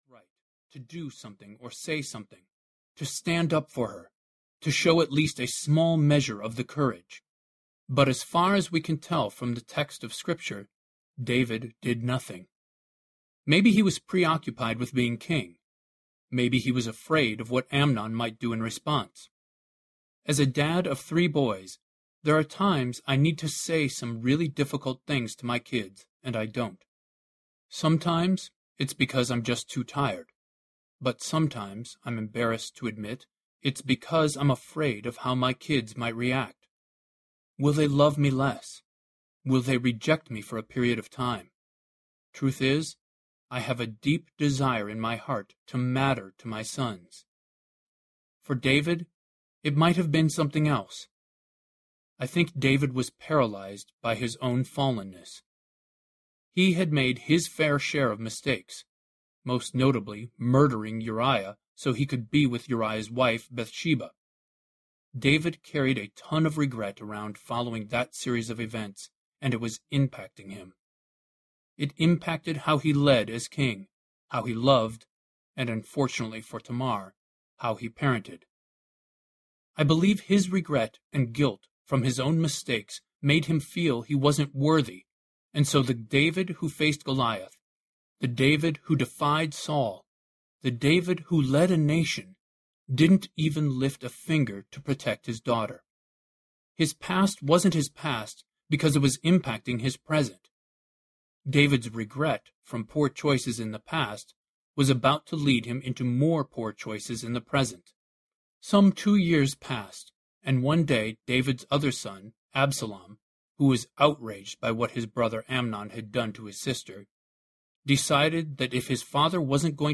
Let Hope In Audiobook
Narrator
5.9 Hrs. – Unabridged